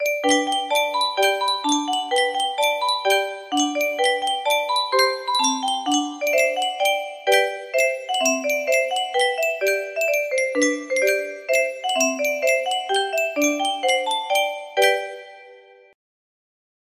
Yunsheng Music Box - Yankee Doodle 188Y music box melody
Full range 60